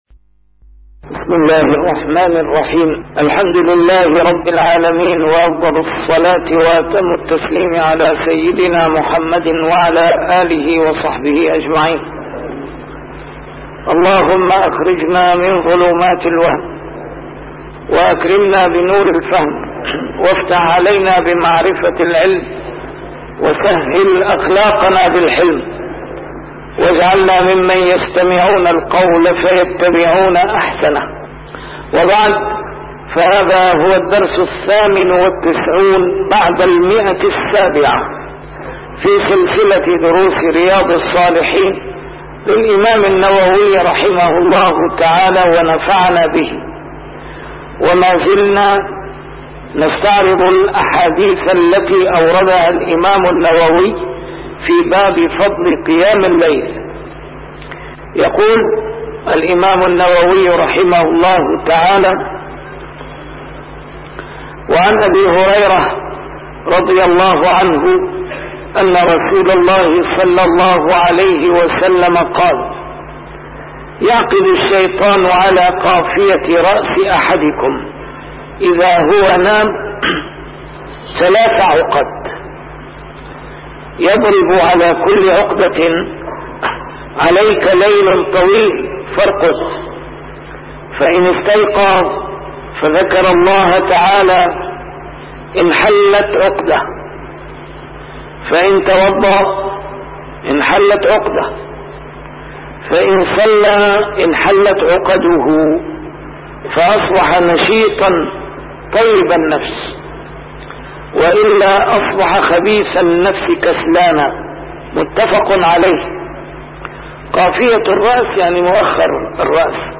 A MARTYR SCHOLAR: IMAM MUHAMMAD SAEED RAMADAN AL-BOUTI - الدروس العلمية - شرح كتاب رياض الصالحين - 798- شرح رياض الصالحين: فضل قيام الليل